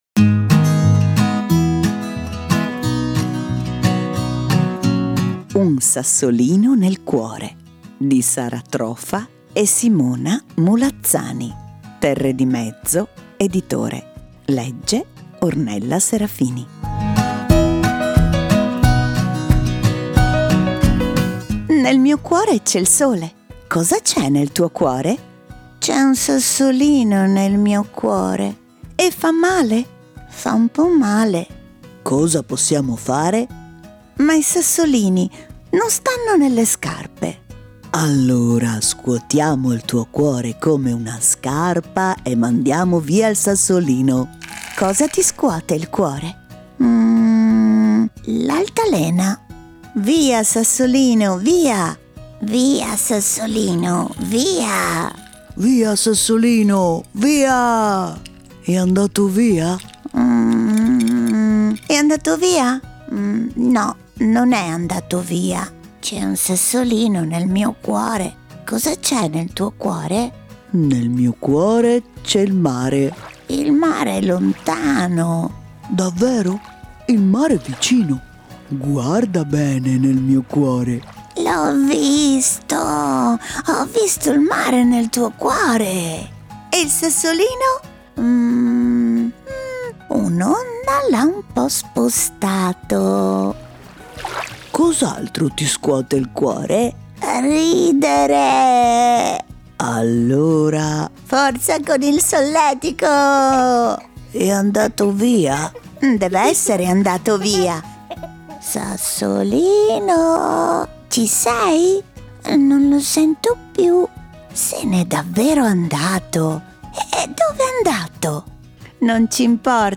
- Un sassolino nel cuore - con tappeto sonoro